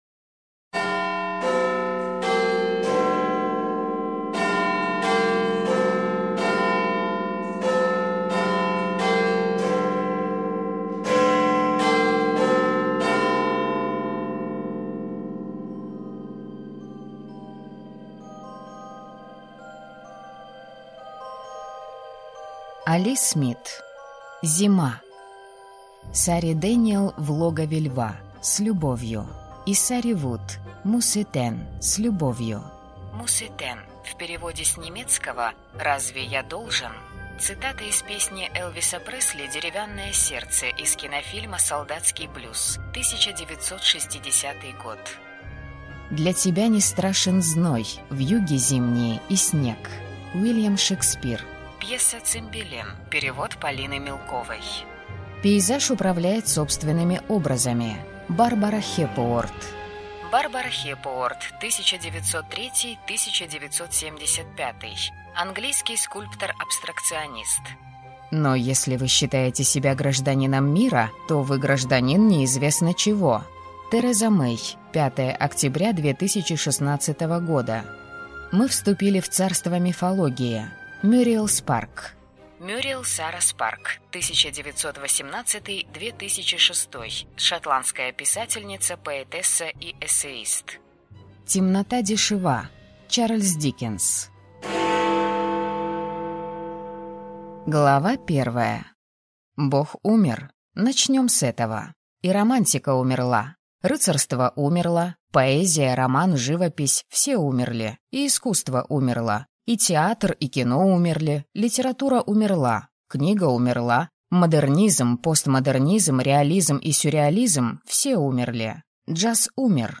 Аудиокнига Зима - купить, скачать и слушать онлайн | КнигоПоиск